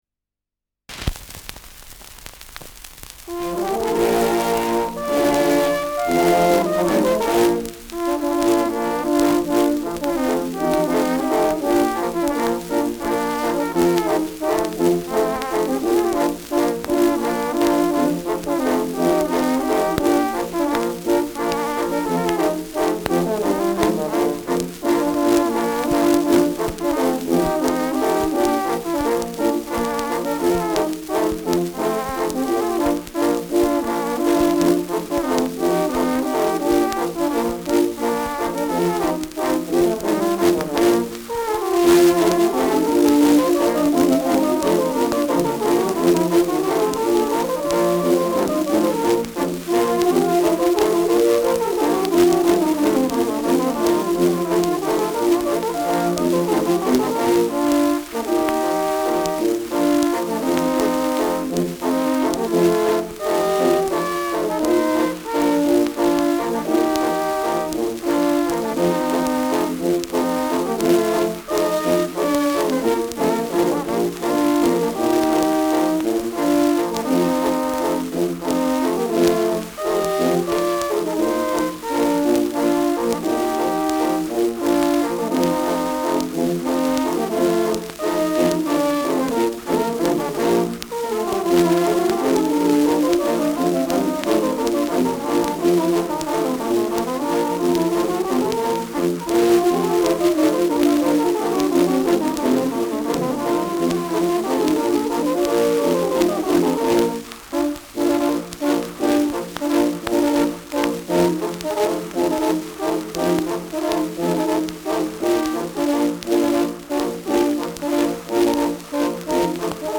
Schellackplatte
Stärkeres Grundrauschen : Gelegentlich leichtes bis starkes Knacken : Verzerrt an lauten Stellen
Kapelle Lutz, München (Interpretation)